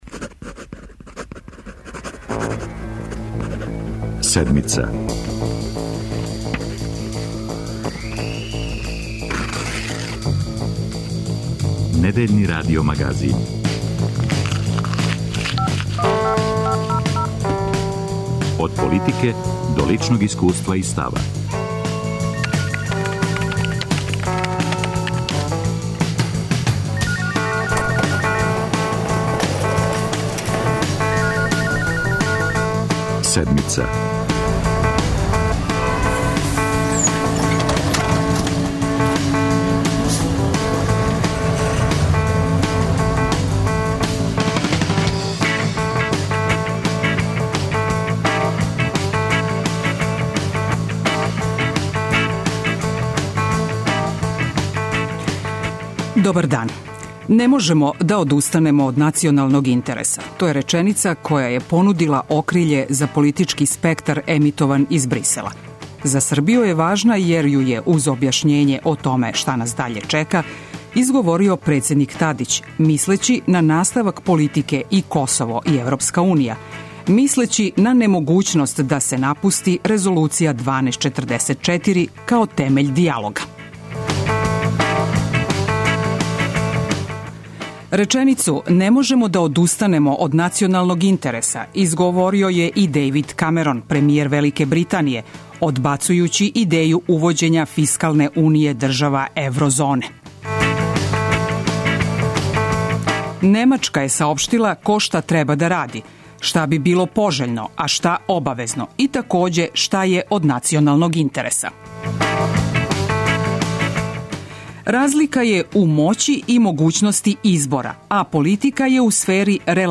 Уживо из Брисела чућемо Мирослава Лајчака, извршног директора ЕУ за Западни Балкан.